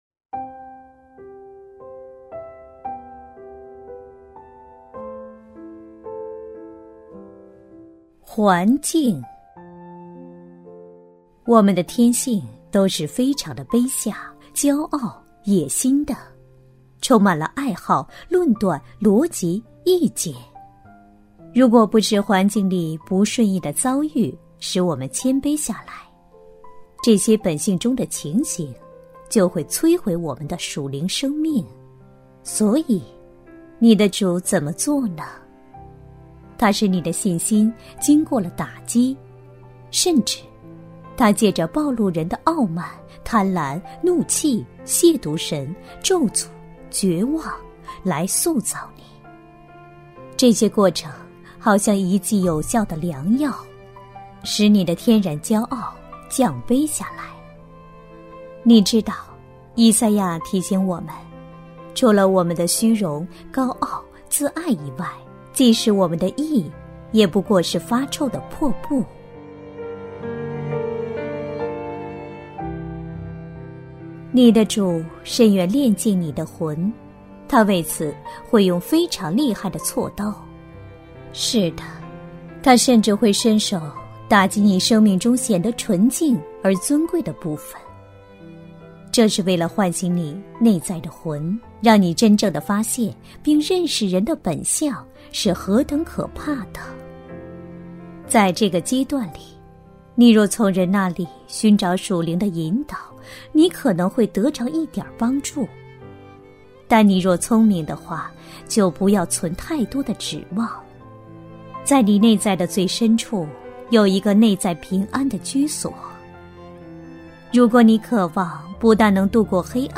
首页 > 有声书 | 灵性生活 | 灵程指引 > 灵程指引 第十篇：环境